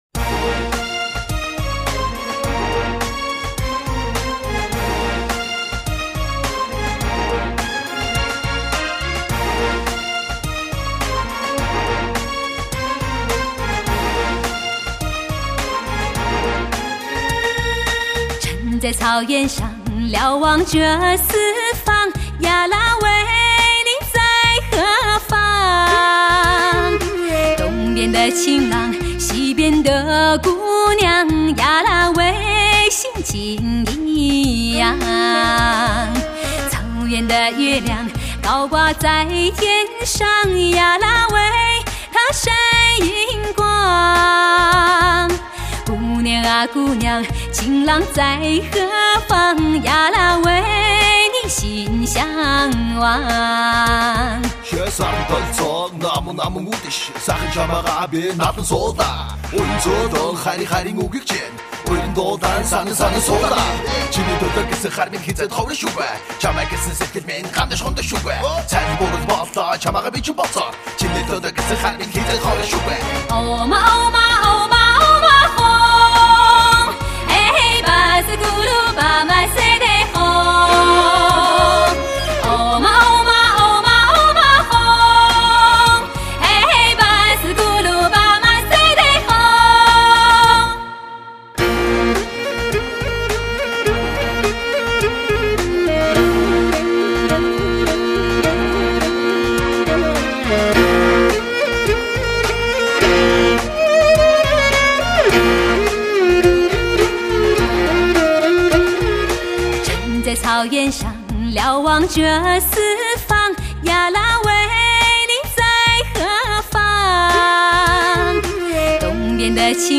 此番深情款款的演唱，势必再次震撼你的心灵！